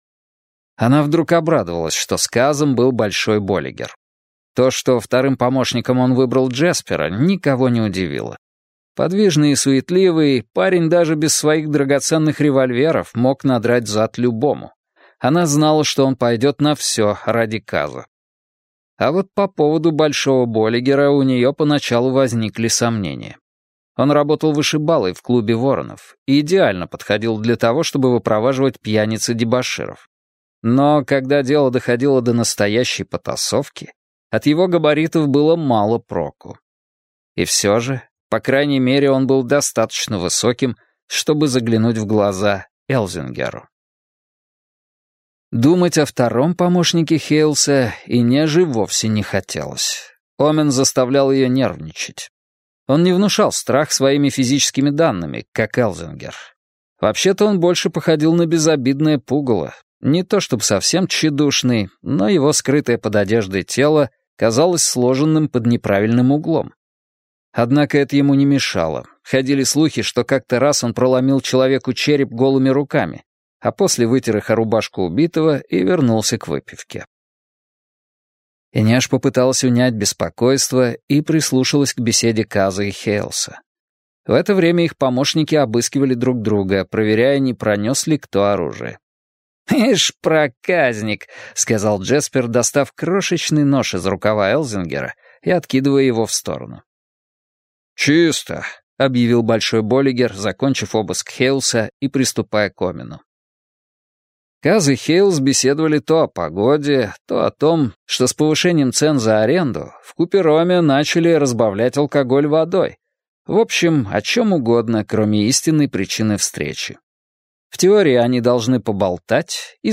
Аудиокнига Шестерка воронов | Библиотека аудиокниг